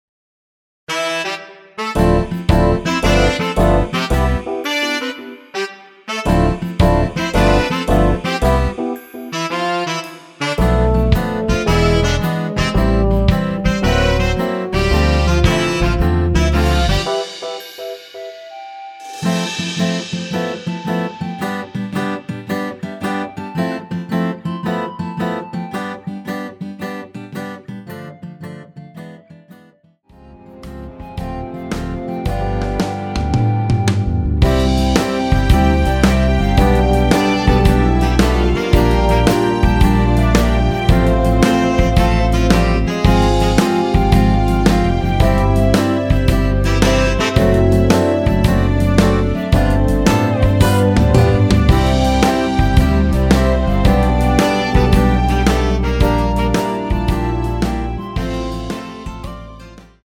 원키에서(-2)내린 멜로디 포함된 MR입니다.(미리듣기 확인)
멜로디 MR이라고 합니다.
앞부분30초, 뒷부분30초씩 편집해서 올려 드리고 있습니다.